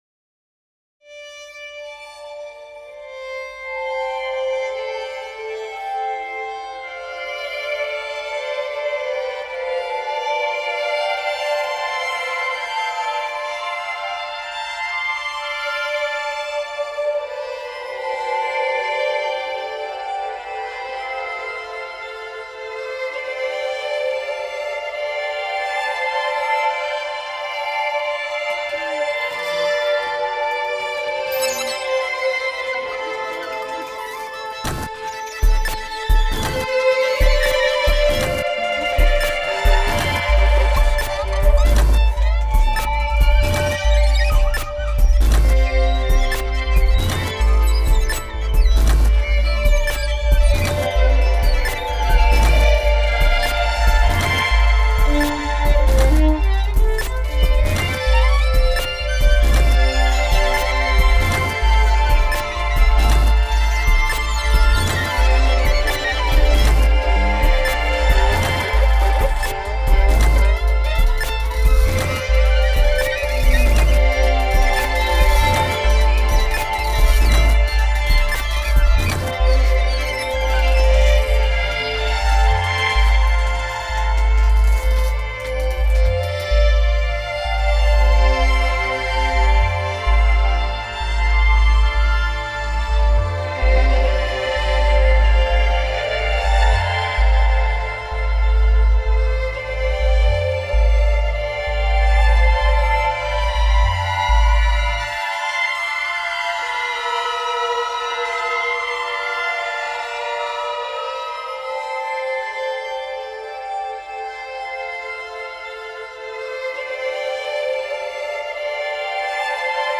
mainly explores digital and analogue synthesis